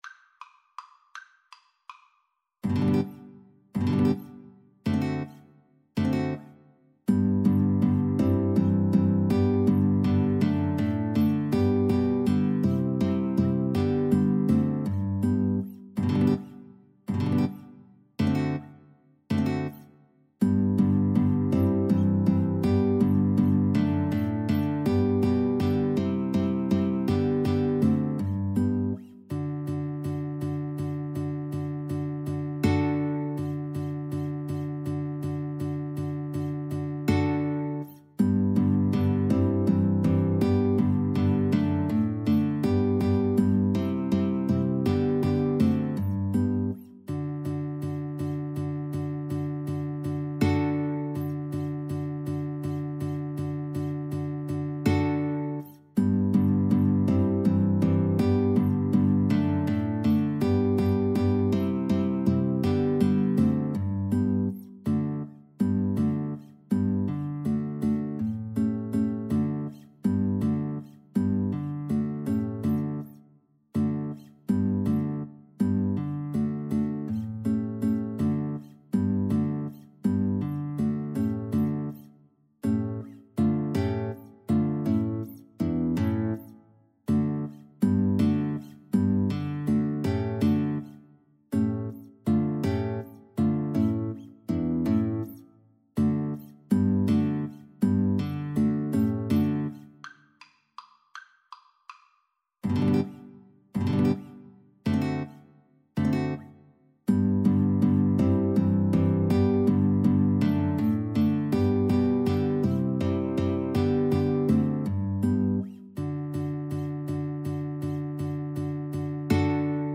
Free Sheet music for Mandolin-Guitar Duet
G major (Sounding Pitch) (View more G major Music for Mandolin-Guitar Duet )
Traditional (View more Traditional Mandolin-Guitar Duet Music)
German